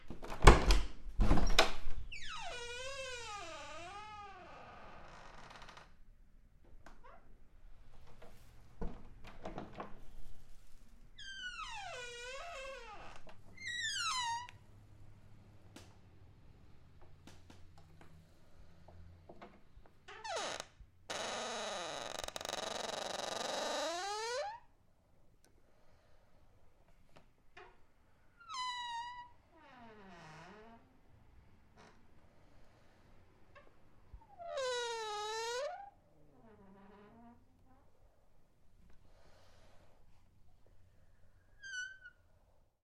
随机的 " 门木质的呆板的公寓吱吱作响的打开和关闭小的温柔的吱吱声
描述：门木多节公寓吱吱作响开放关闭小温柔creaks.flac
Tag: 打开 关闭 温柔的 摇摇欲坠的 吱吱作响 公寓 粗糙